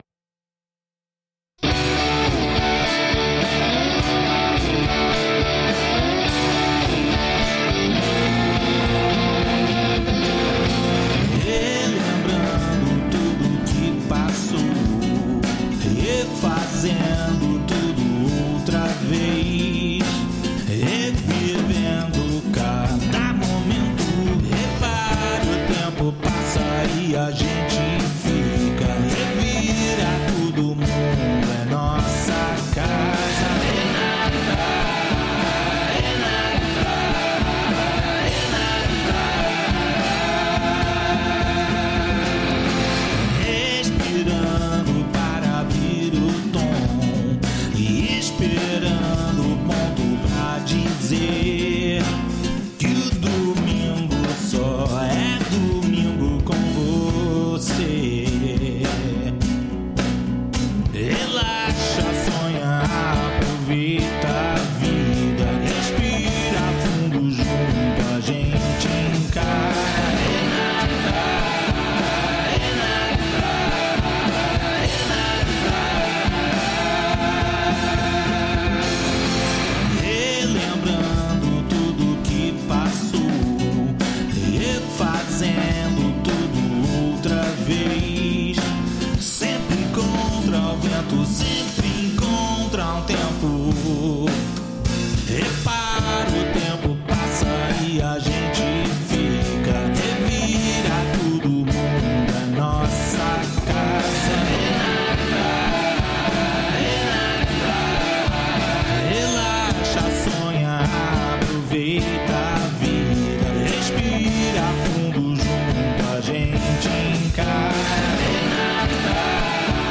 voz, guitarras, violões, baixo e bateria eletrônica